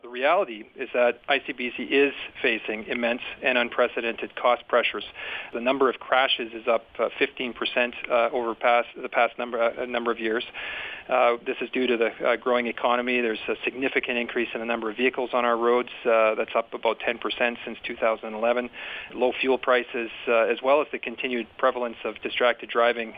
Transportation and Infrastructure Minister Todd Stone says there are a number of cost pressures on ICBC.